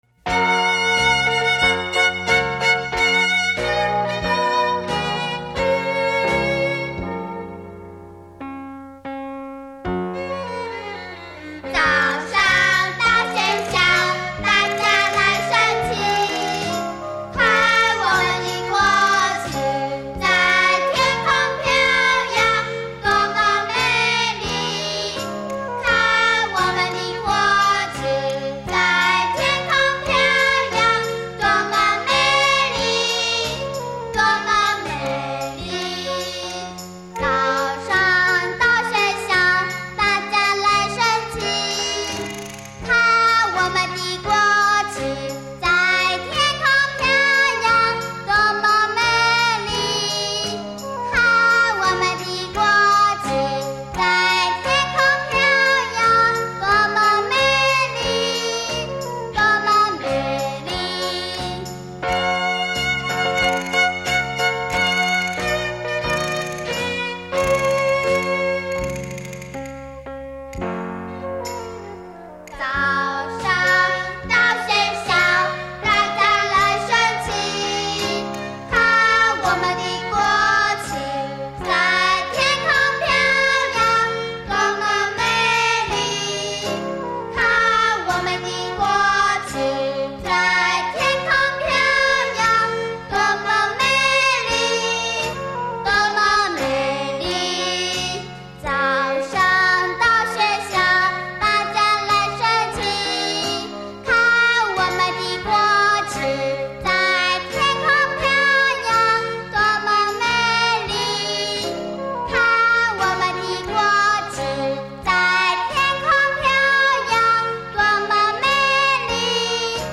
新年歌曲